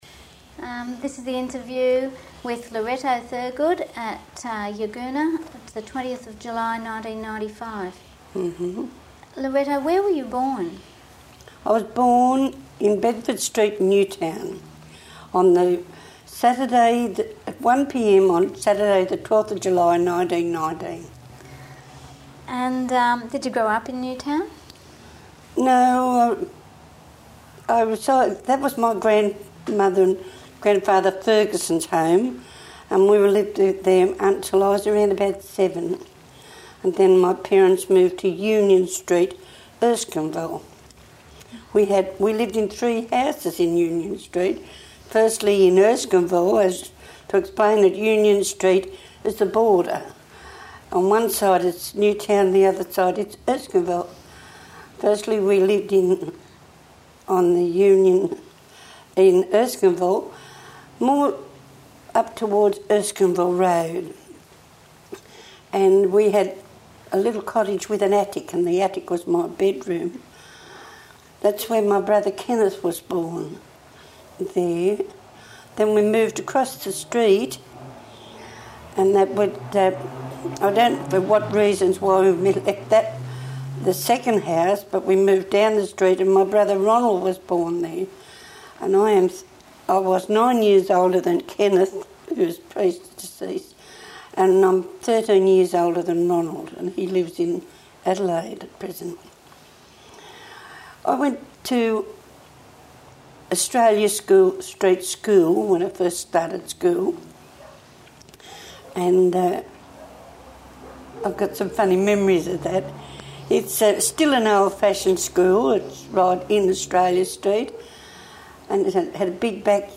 This interview is part of the City of Sydney's oral history project: Life in c20th South Sydney